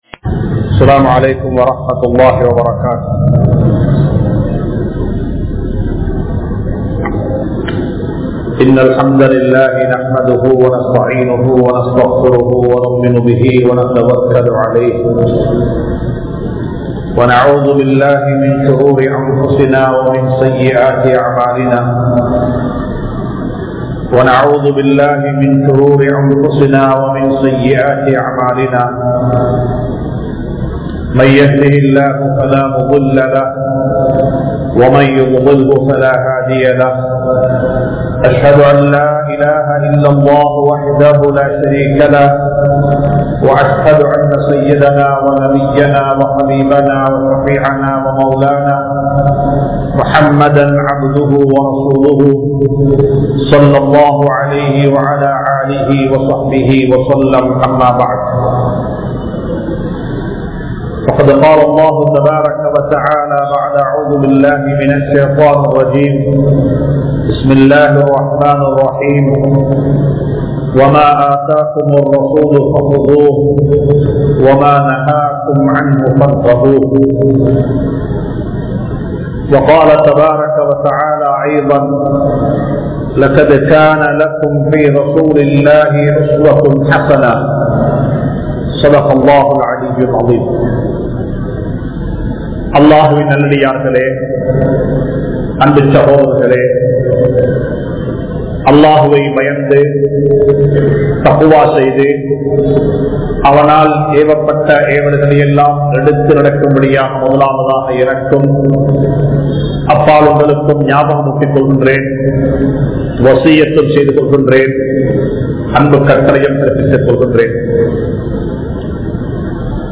Sunnaththaana Noanpuhal (ஸூன்னத்தான நோன்புகள்) | Audio Bayans | All Ceylon Muslim Youth Community | Addalaichenai
Majma Ul Khairah Jumua Masjith (Nimal Road)